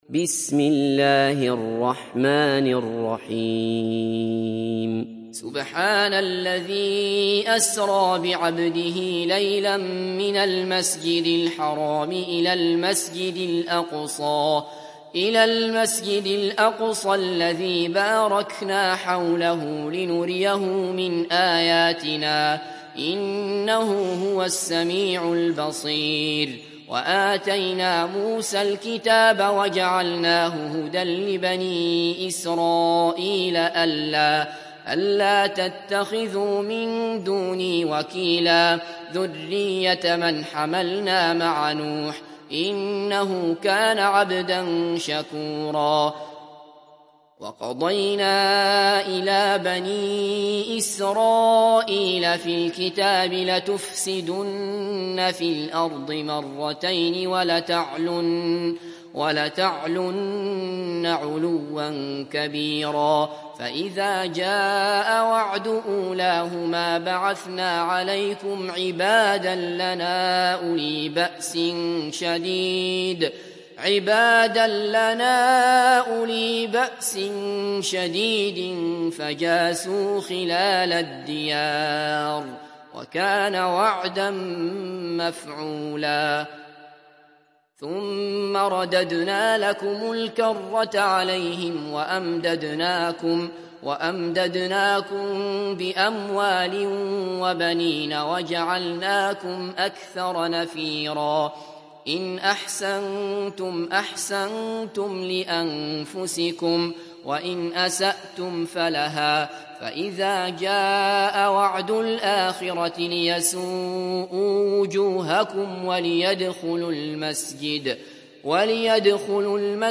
Sourate Al Isra Télécharger mp3 Abdullah Basfar Riwayat Hafs an Assim, Téléchargez le Coran et écoutez les liens directs complets mp3